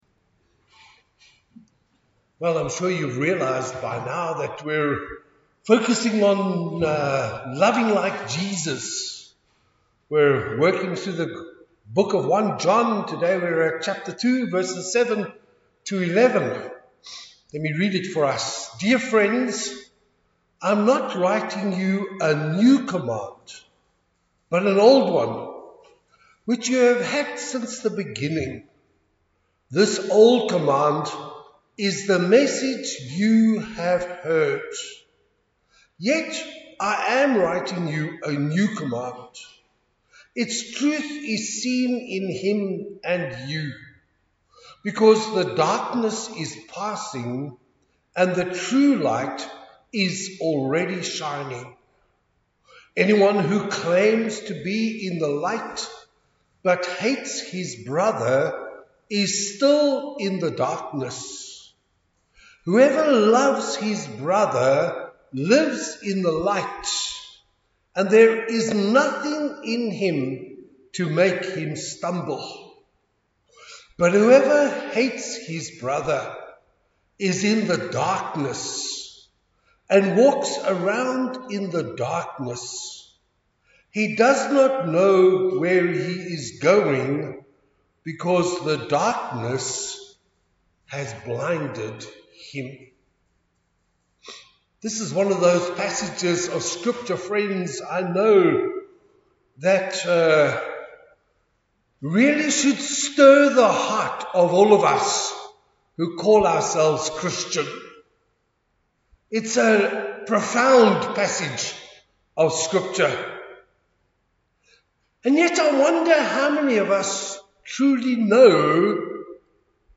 Sunday Service – Page 4 – Bethany Emmanuel Baptist Church